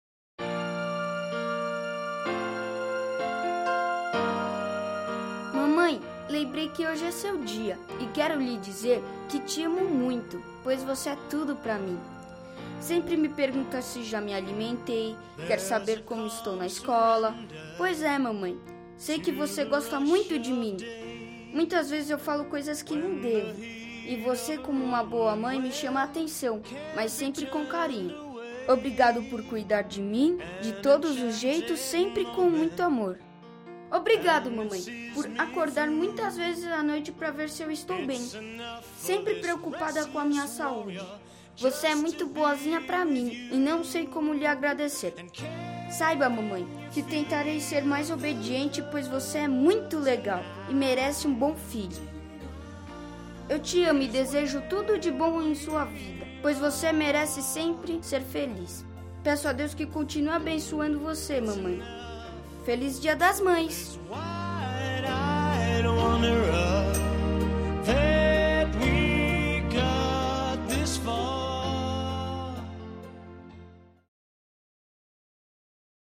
18-Mamae-eu-te-amo-filho-crianca-M.mp3